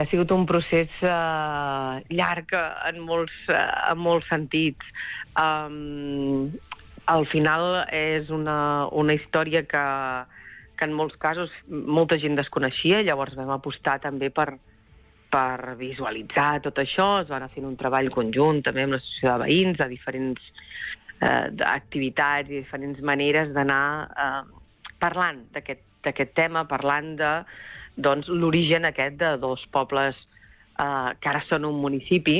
L’alcaldessa de Palamós, Maria Puig, ha valorat positivament les primeres setmanes al càrrec, en una entrevista concedida al Supermatí de Ràdio Capital.